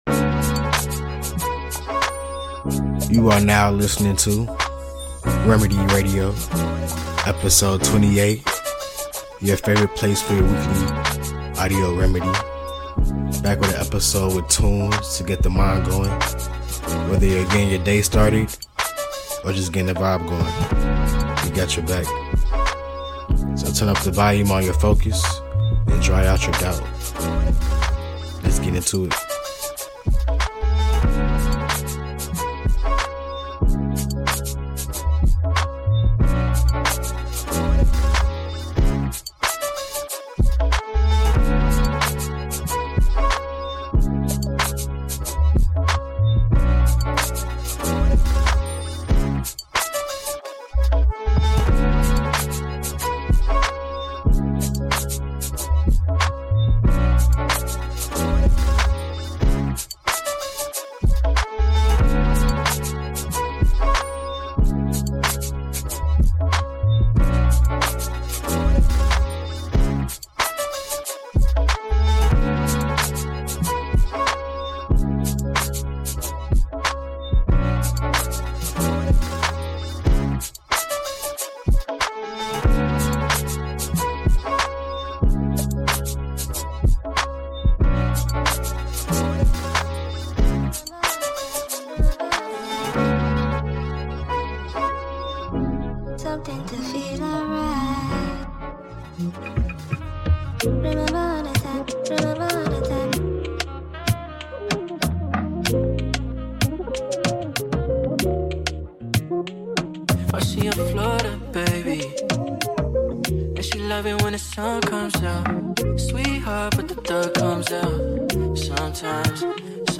Sounds of Afro-beats and Sounds of Hip-Hop in One.